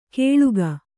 ♪ kēḷuga